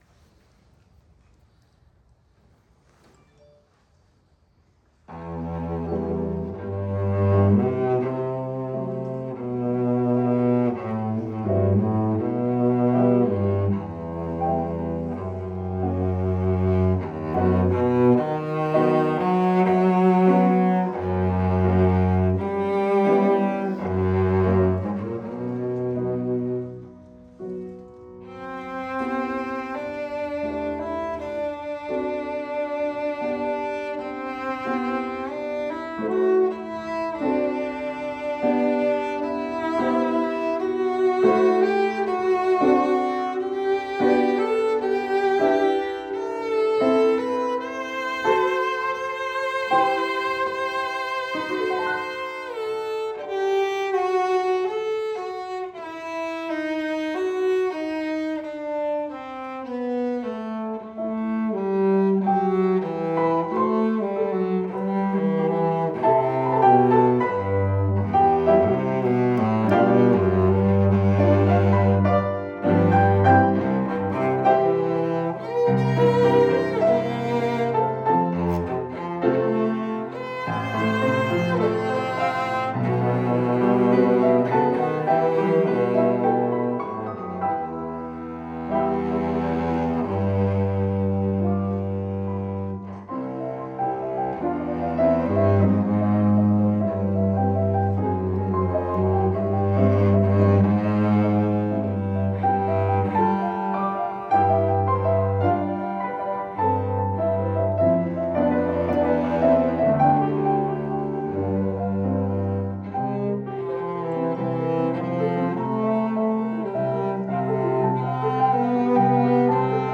My cello performance of a cello-piano duet, with recording.
Certainly the piece is interesting in that it covers the whole range of the cello - from the bottom note up to almost as high as you can go. It has sweet parts and rowdy parts.